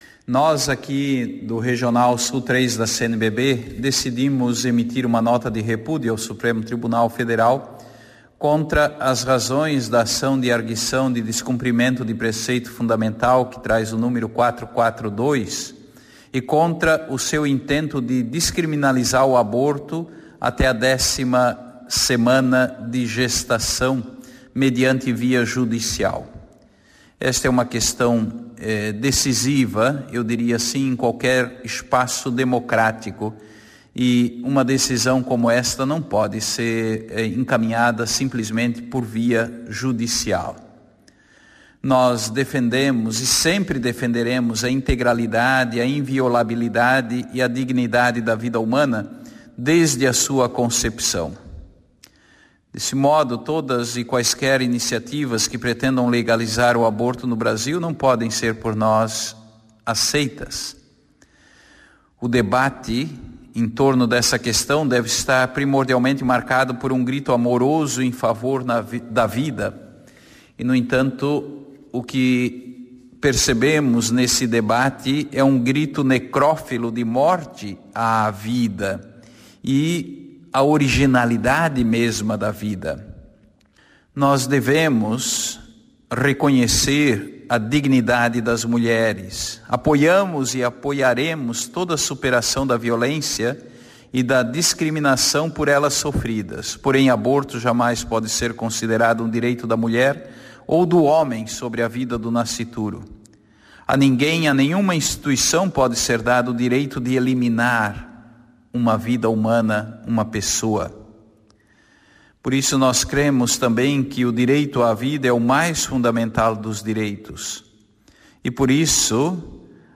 Ouça na integra a entrevista com Dom Jaime Spengler